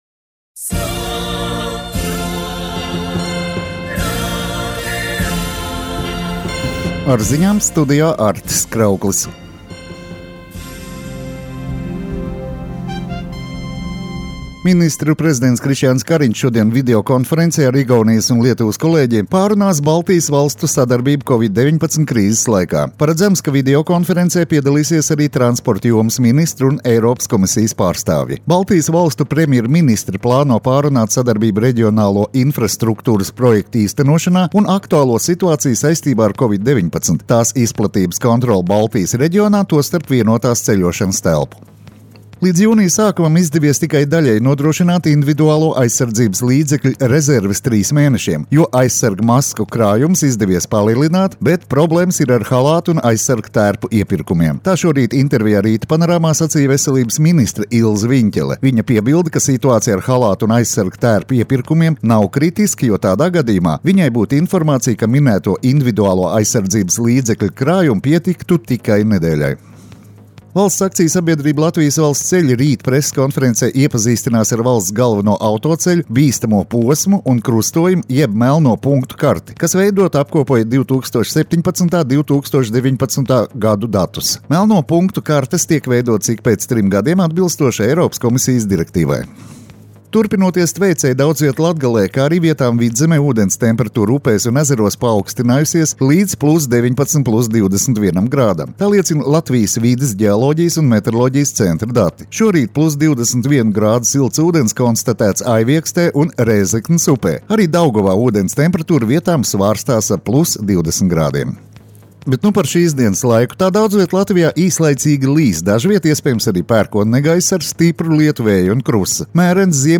Radio Skonto ziņas Rīta Programmā 10.06.